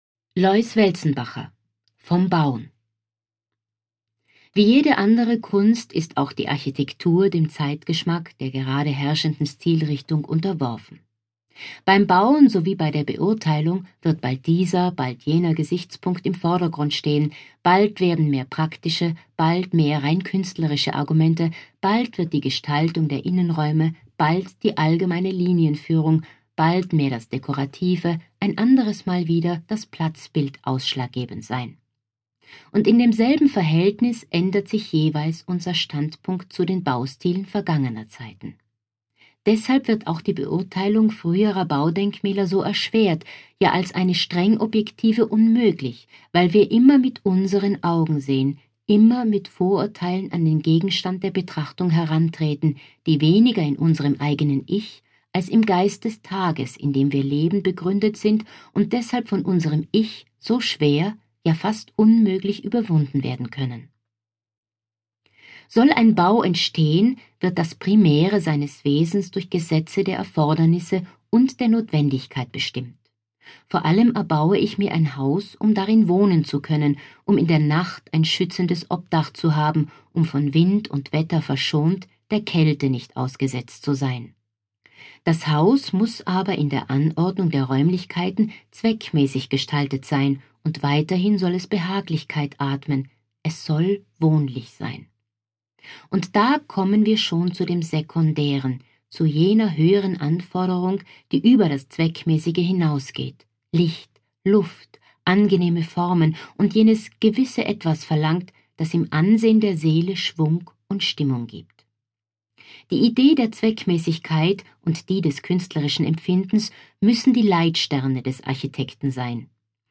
Auszüge aus der Publikation "reprint. ein lesebuch zu architektur und tirol"